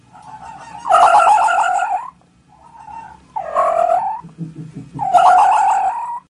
Fox sound ringtone free download ringtone free download
Animals sounds